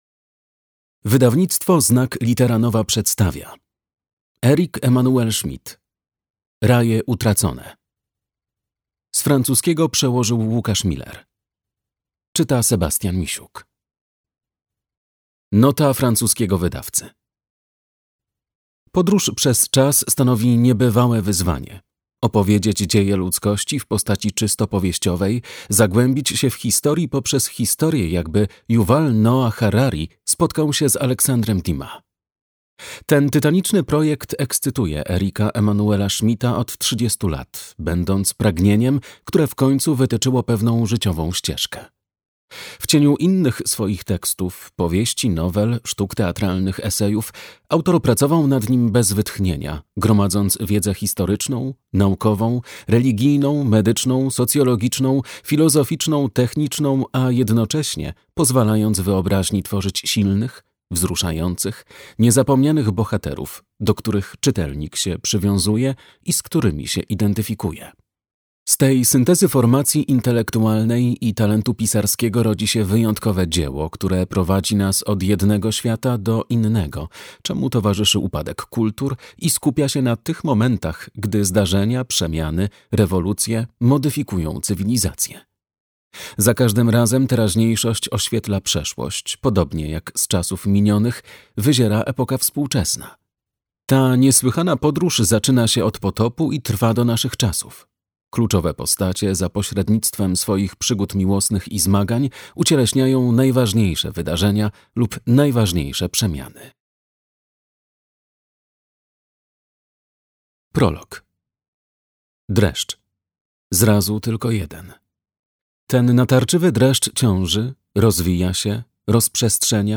Raje utracone - Eric-Emmanuel Schmitt - audiobook